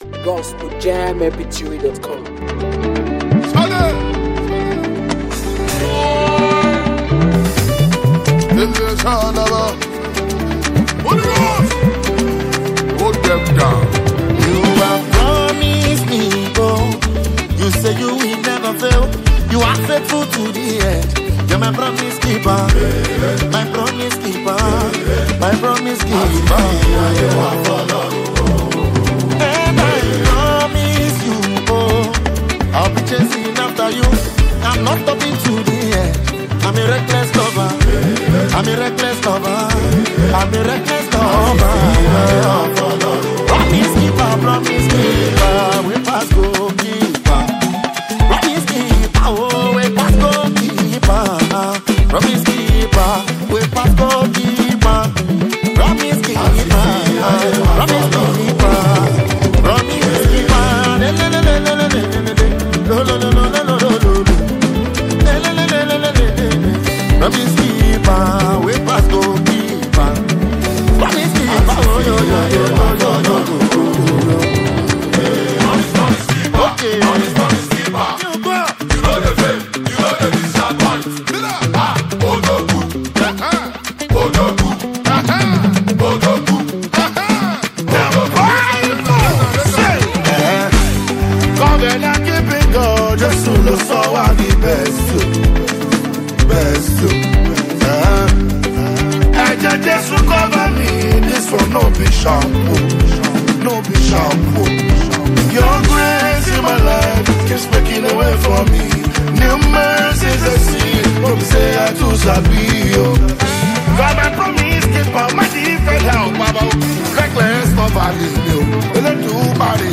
Afro beatmusic